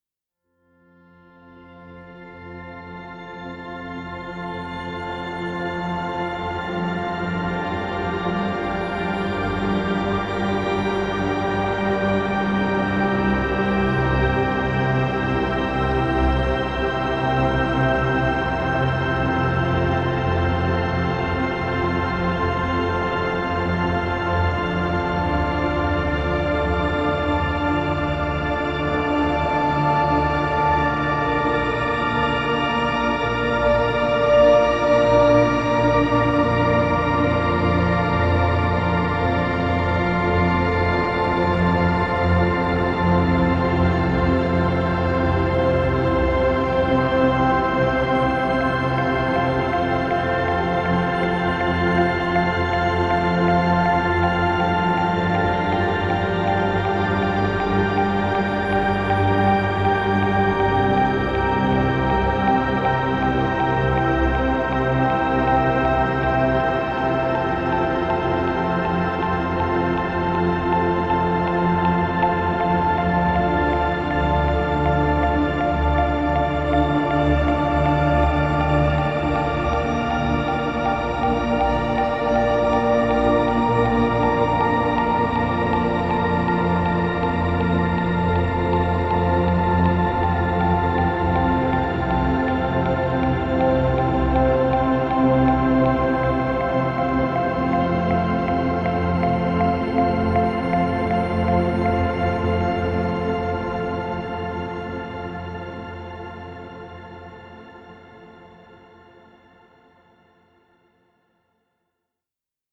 Space-Ambient-3.mp3